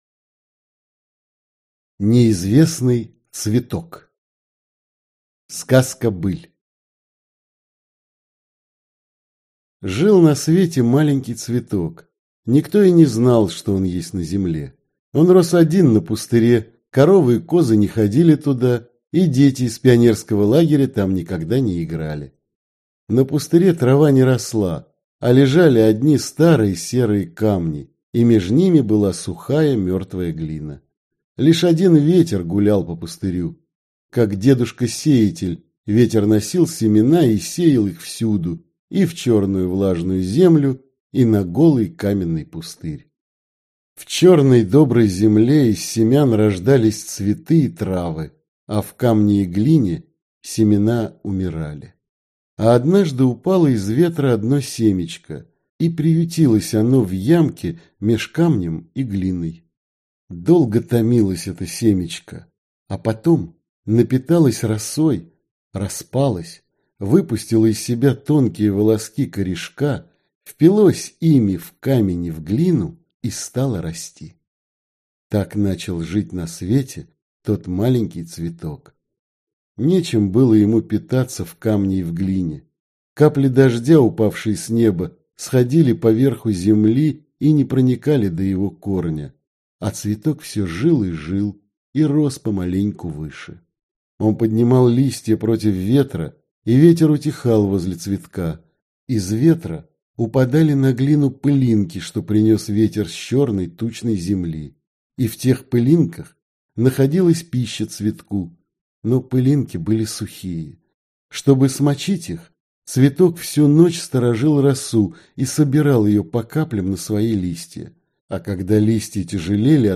Аудиокнига Потомки солнца | Библиотека аудиокниг